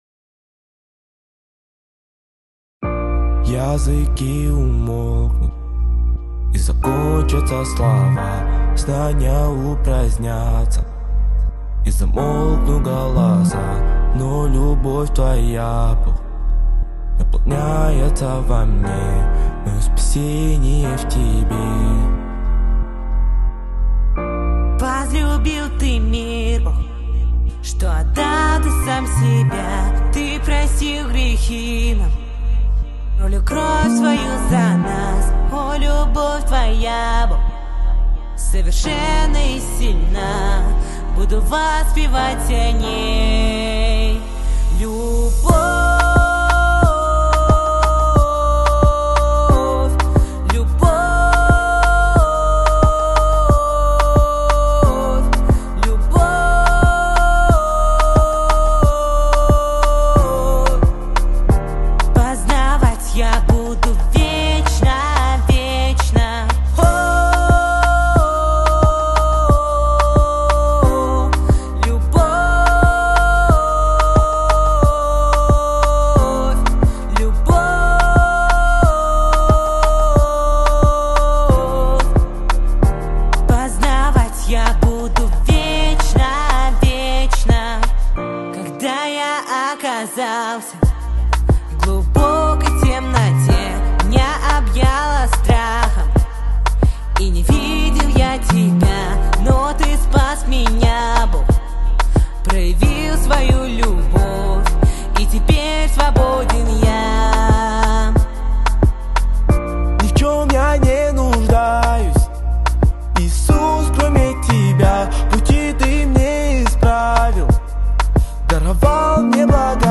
песня
395 просмотров 504 прослушивания 13 скачиваний BPM: 90